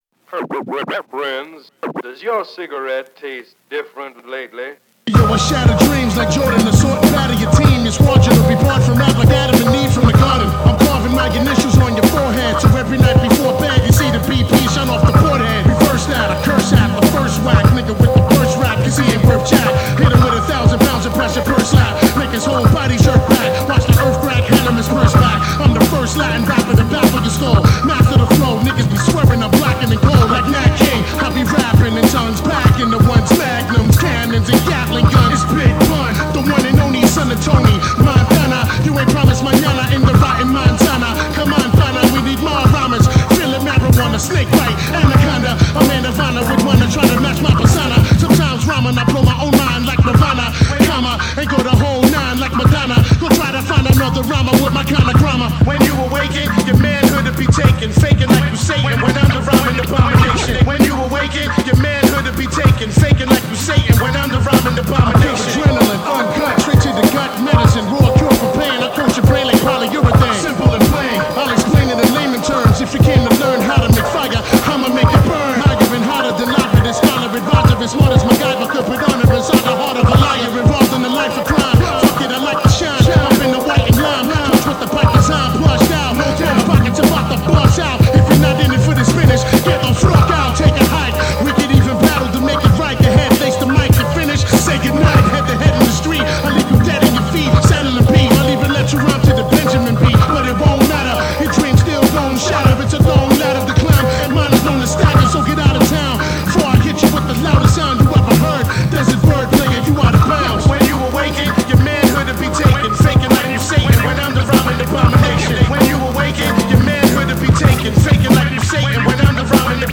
Same formula with the main beef being cooked on OT, an external scratch intro that I got from an old blooper record, some drums, and Big Pun. Otherwise, all the other sounds are from the sample track.
Beat + Big Pun